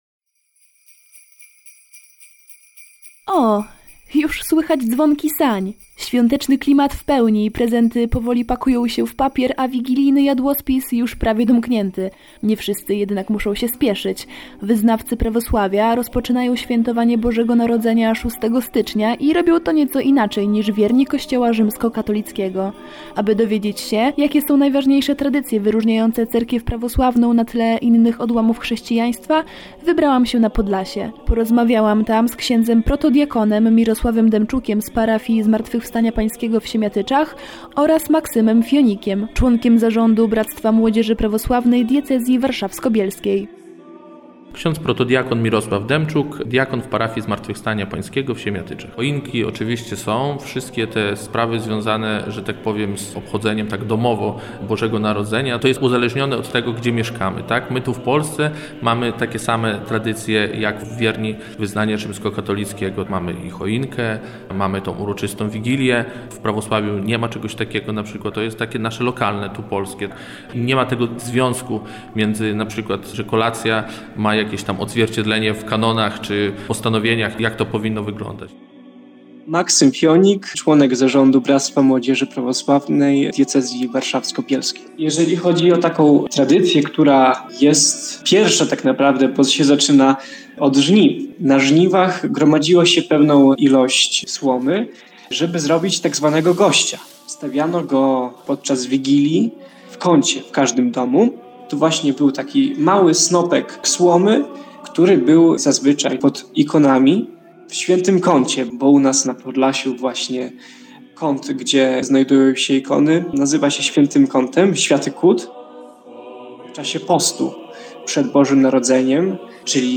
Świetny materiał i cudny głos Pani prowadzącej 😊 chcemy więcej!